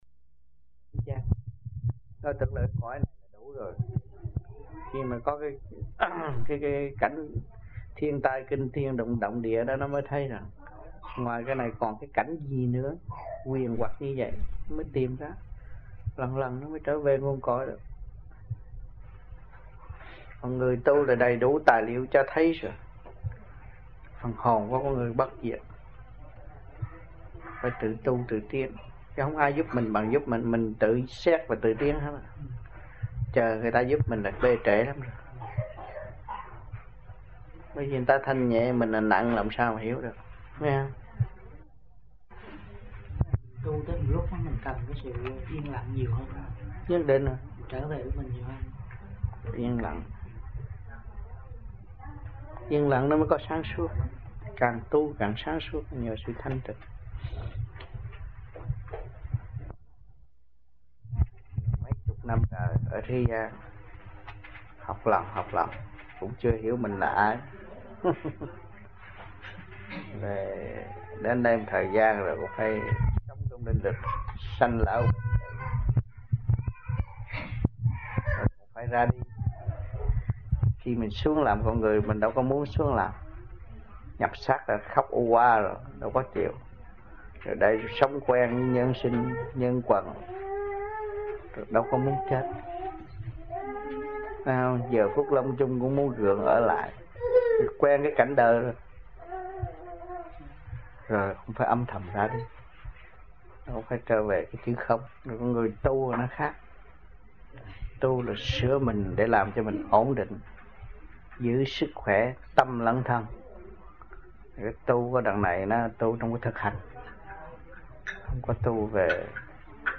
1980-11-20 - AMPHION - THUYẾT PHÁP 06